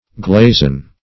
Search Result for " glazen" : The Collaborative International Dictionary of English v.0.48: Glazen \Glaz"en\, a. [AS. gl[ae]sen.]